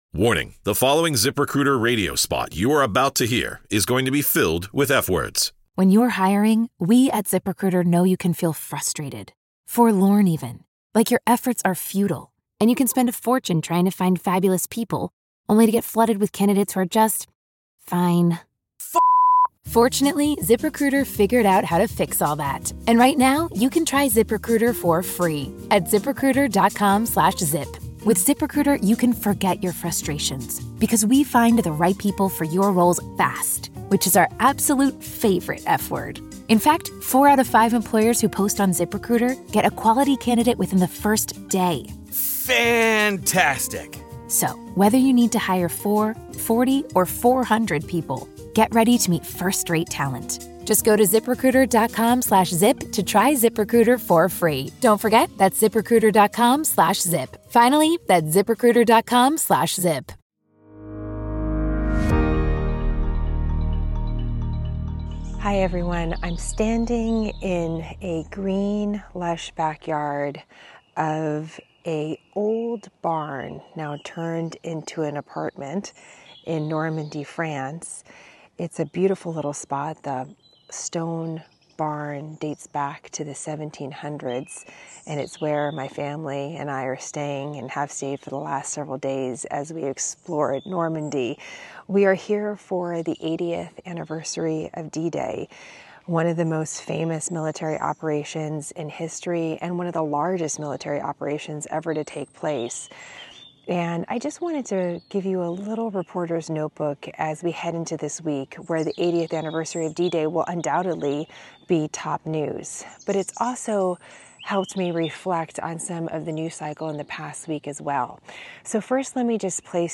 REPORTER'S NOTEBOOK: On The Ground In Normandy, France SmartHERNews Jenna Lee Education, News 5 • 615 Ratings 🗓 2 June 2024 ⏱ 15 minutes 🔗 Recording | iTunes | RSS 🧾 Download transcript Summary From the backyard of an 18th century barn, Jenna shares initial reflections of her travels to Normandy, France to mark the 80th anniversary of D-Day.